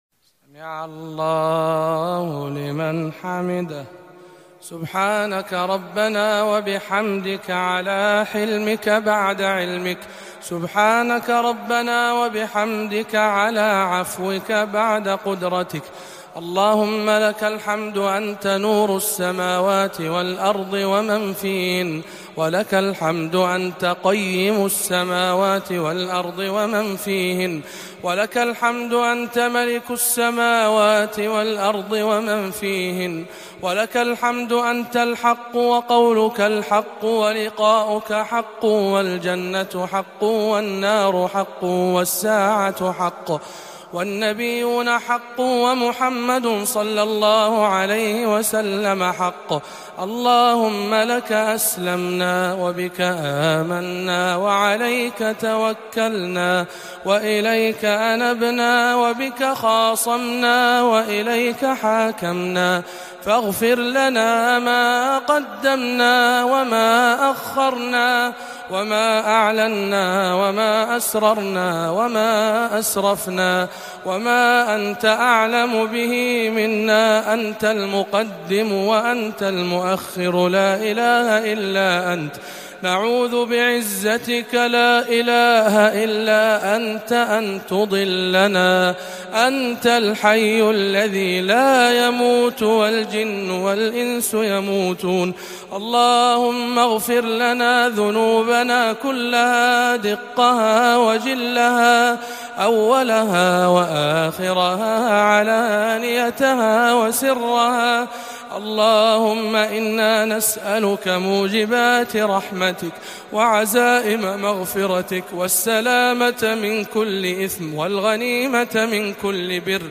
45. أدعية القنوت (16) - رمضان 1438 هـ